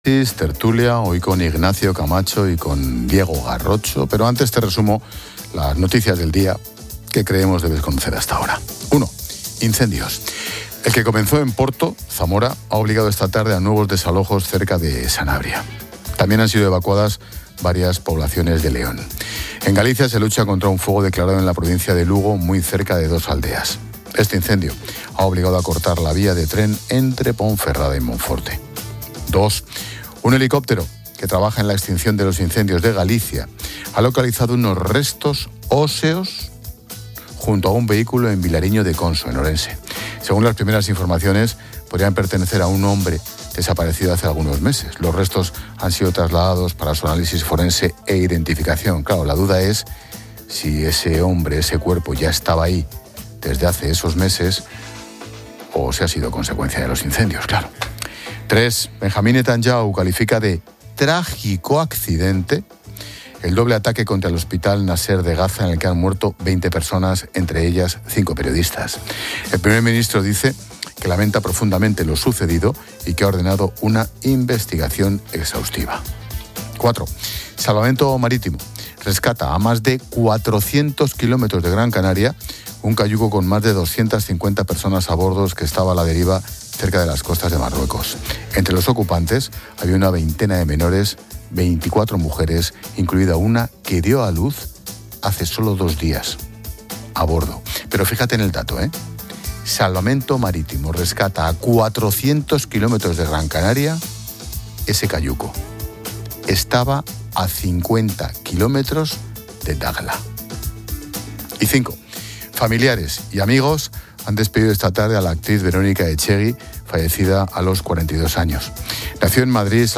La tertulia se centra en la gestión política de los incendios, criticando la falta de claridad en las responsabilidades entre el gobierno central y las comunidades autónomas.